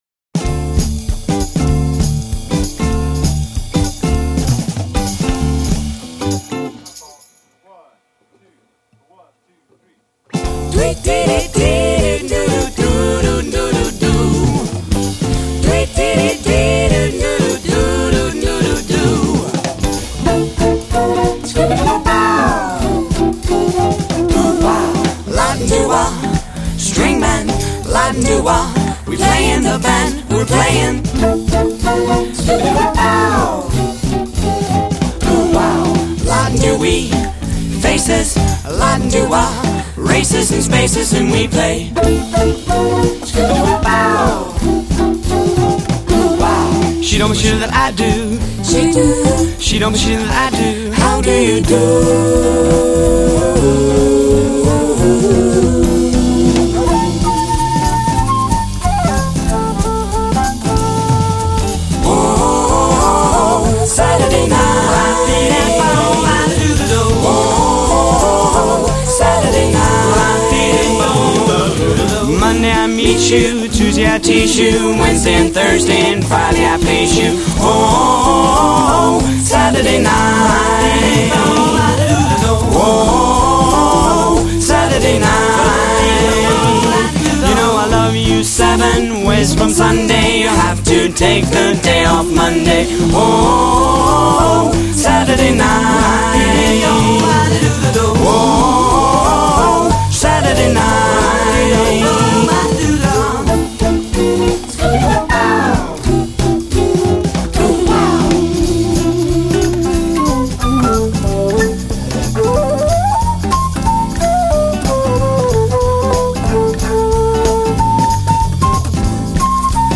It as that mid sixties Michel LeGrand sound to it .
that is definitely some great flute on there